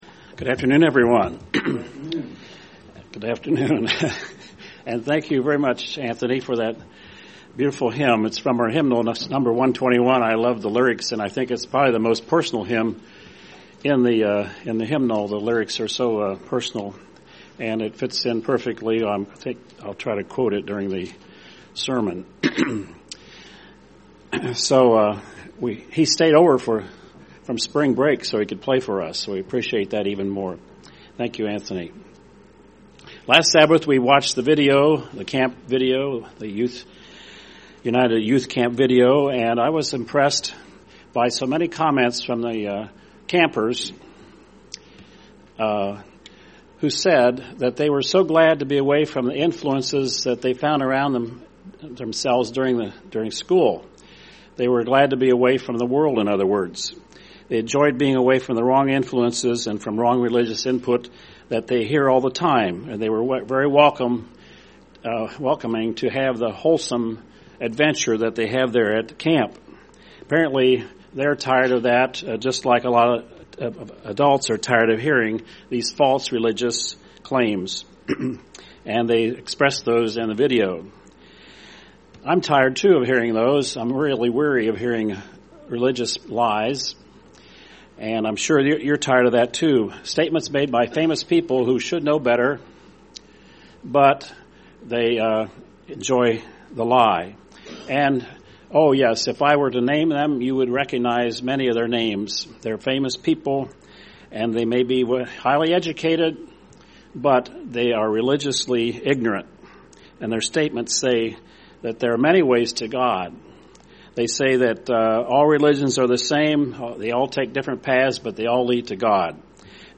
Given in Cincinnati East, OH
UCG Sermon Studying the bible?